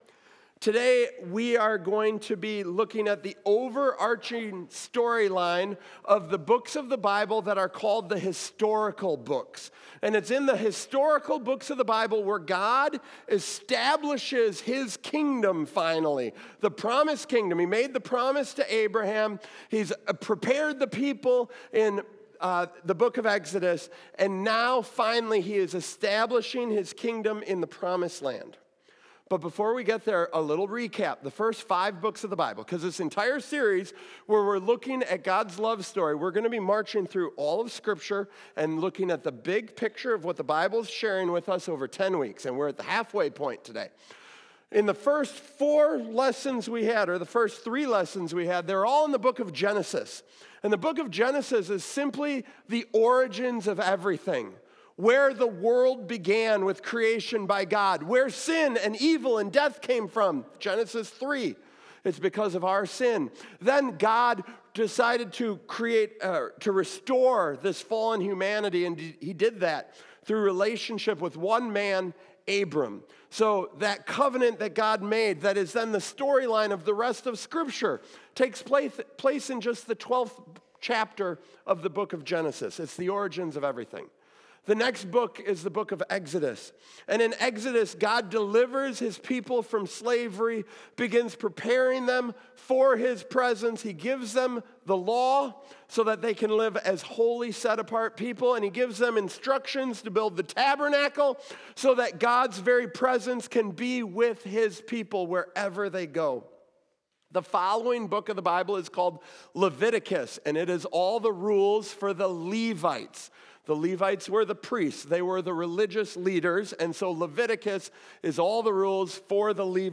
What does it really mean that God is King? In this sermon, we explore the historical books of the Bible to see how God established His kingdom—leading Israel in battle, giving them laws, and providing for their needs.